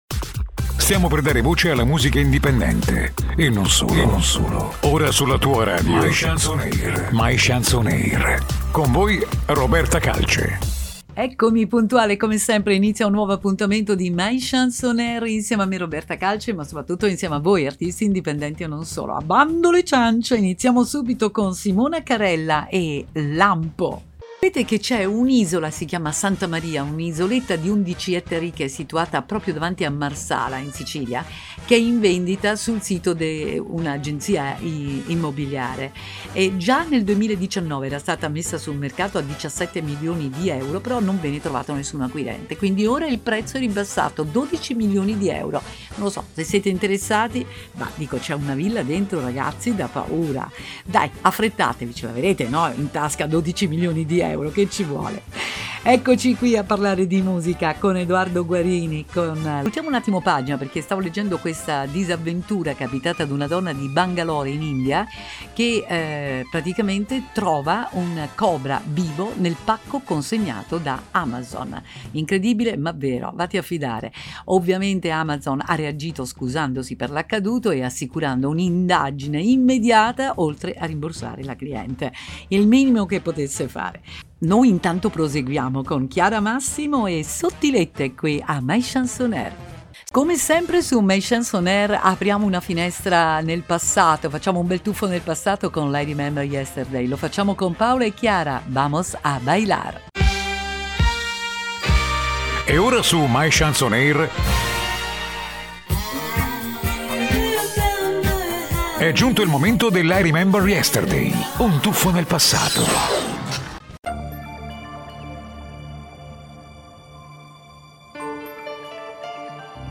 : Proposta programma radiofonico settimanale gratuito: MyChance On Air